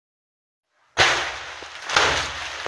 Sonido_fuerte.wav